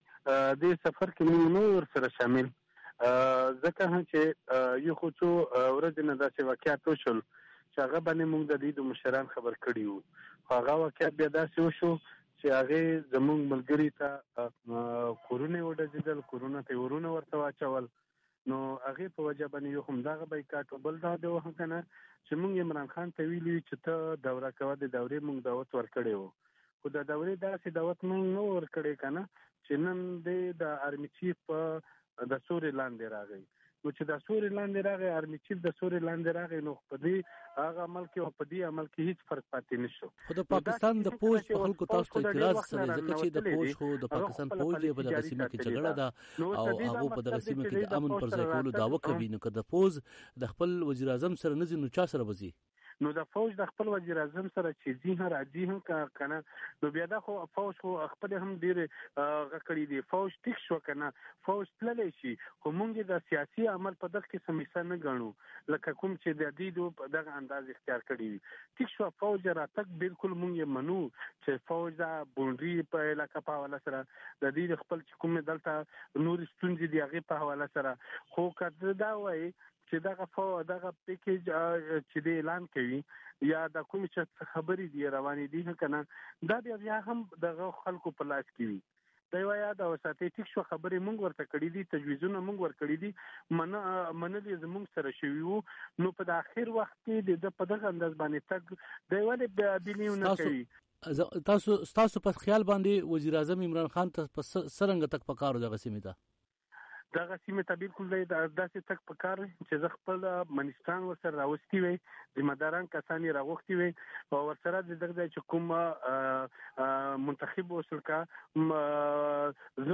علي وزير او محسن داوړ وي او ای ډیوه ته په ځان ځانله مرکو کې ويلي دي چې وزیراعظم عمران خان ته د سيمې د سفر بلنه دويي ورکړې وه خو چې کله وزیراعظم سيمې ته تللو نو د دويي سره یې صلاح نه وه کړې.